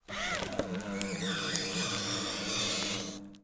На этой странице собраны звуки работы шредера — от плавного жужжания до резкого измельчения бумаги.
Шредер застрял из-за бумаги и перестал работать